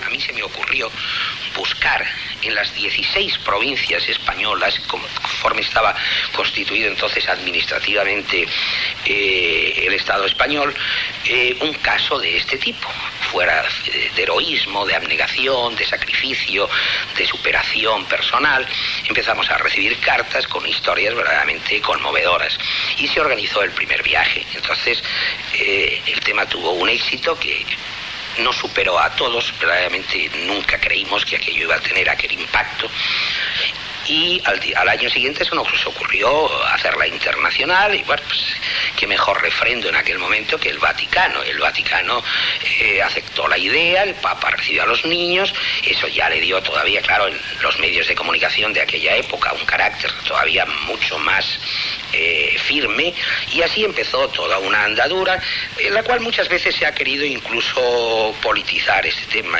Programa presentat per Joan Manuel Serrat.
Fragment extret del programa "La radio con botas", emès per Radio 5 l'any 1991